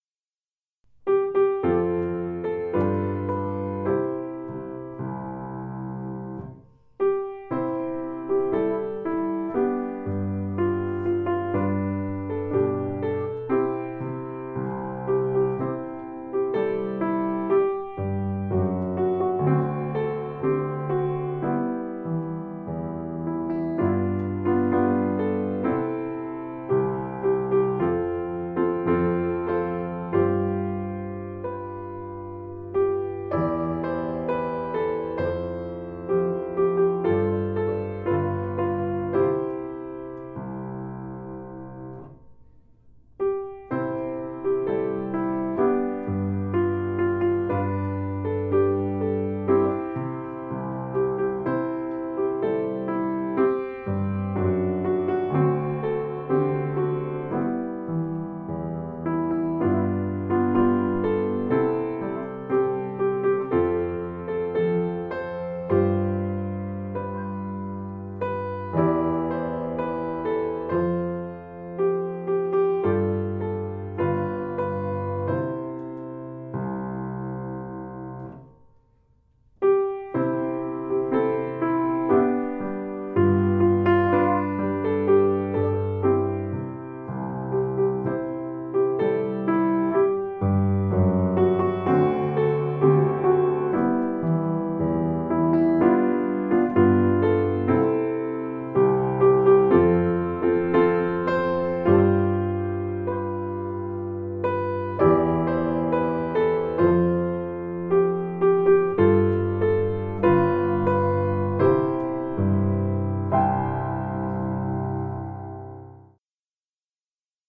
piano seul